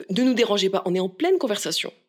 VO_ALL_EVENT_Trop proche de la cible_01.ogg